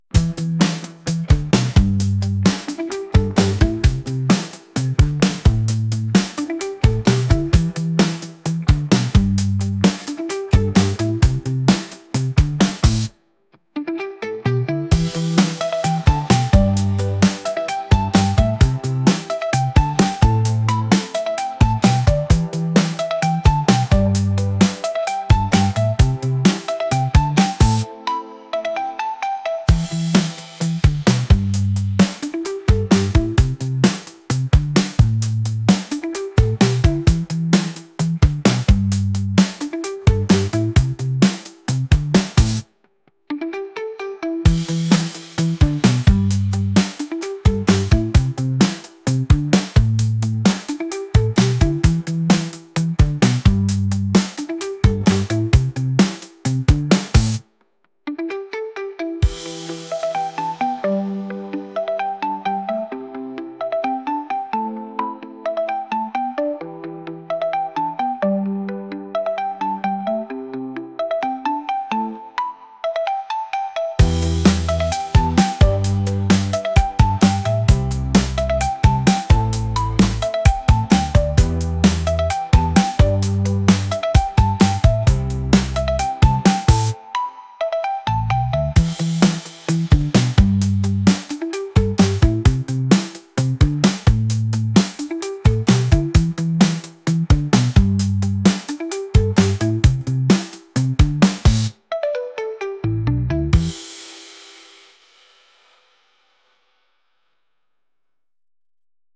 Soul
Groovy, Energetic
129 BPM